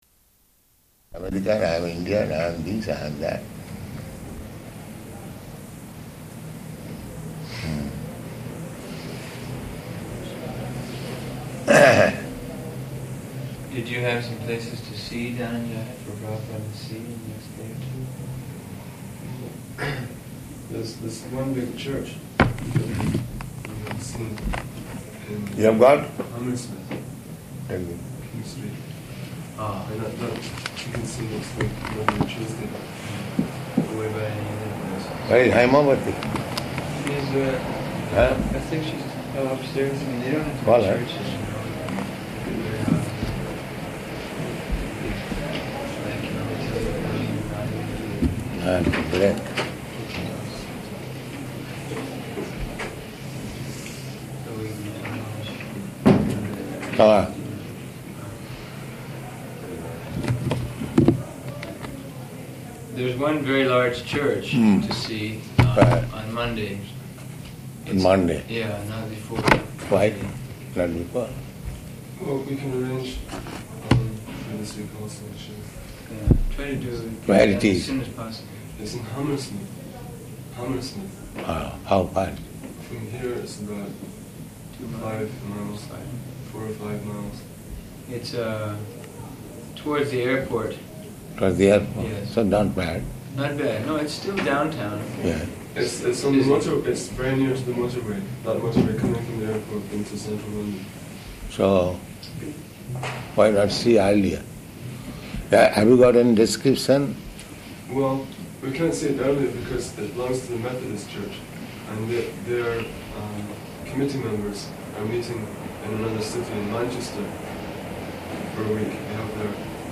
Room Conversation
Room Conversation --:-- --:-- Type: Conversation Dated: August 1st 1972 Location: London Audio file: 720801R1.LON.mp3 Prabhupāda: "...American," "I am Indian," "I am this," "I am that."